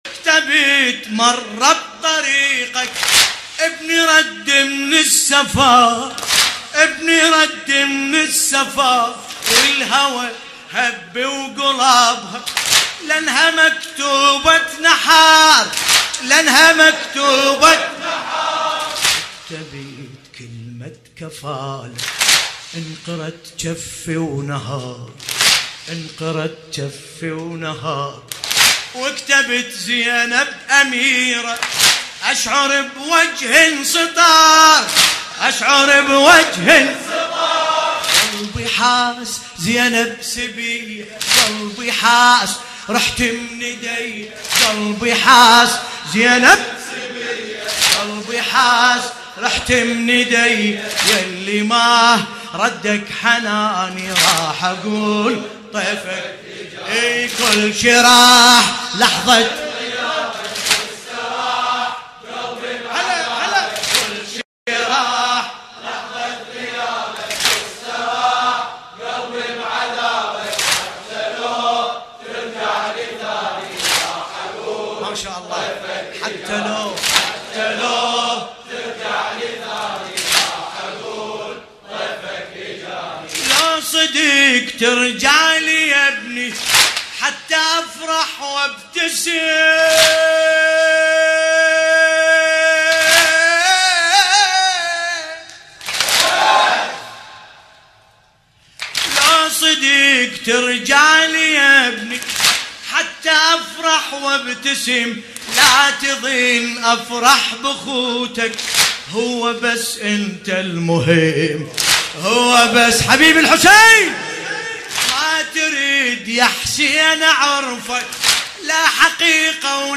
ملف صوتی لیلة 4 محرم بصوت باسم الكربلائي